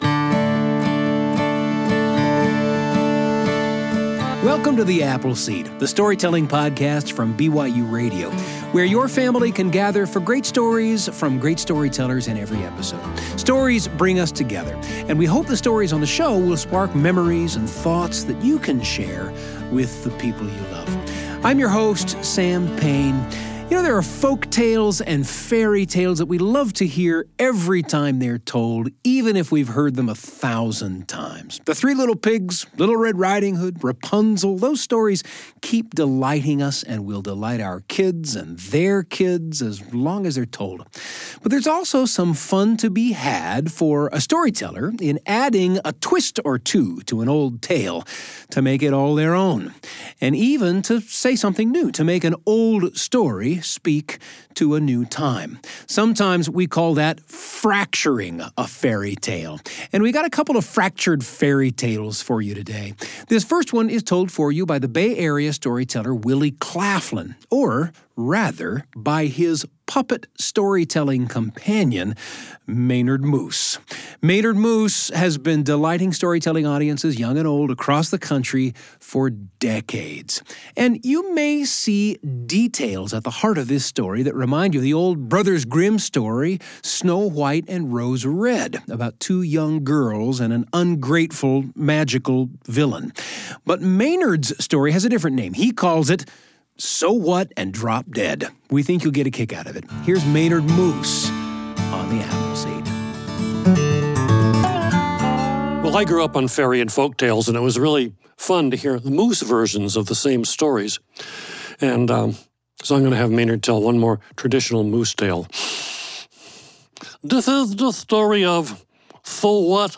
The Apple Seed is a storytelling podcast by BYUradio